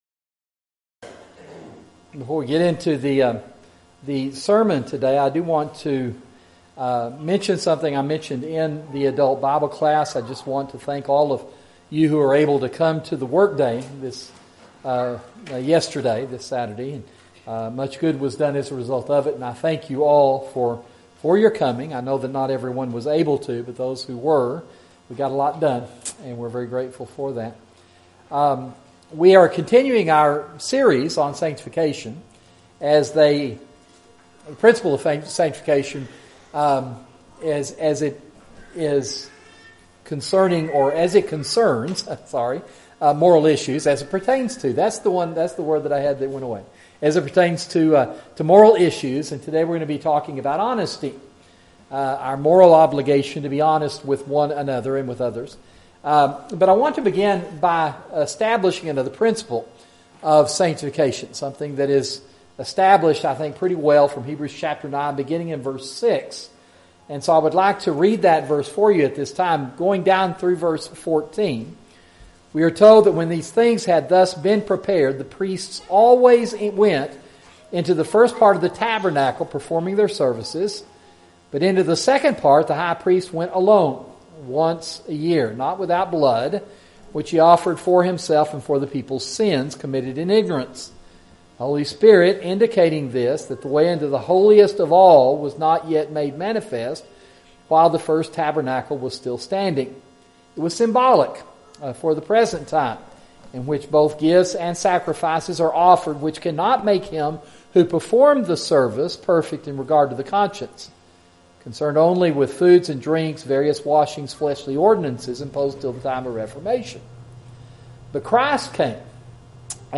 Sermon: Sanctification and Sexuality